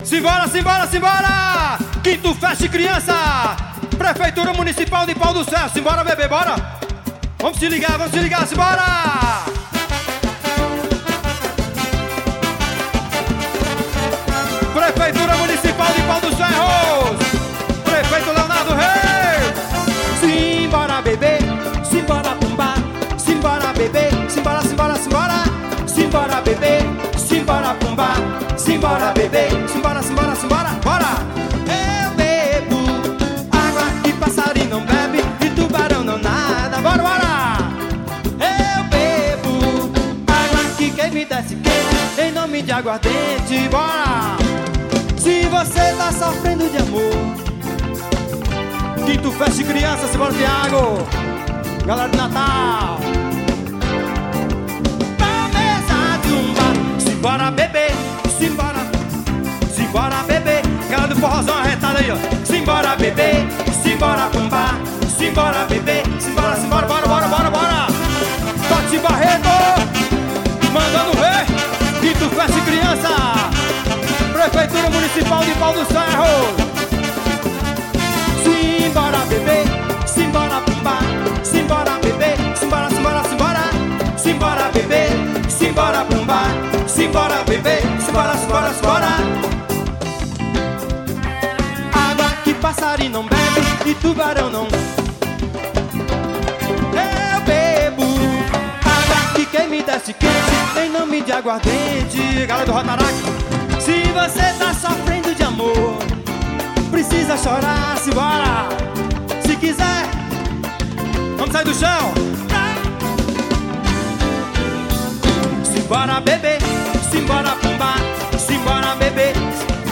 ao vivo Fest Criança 2009.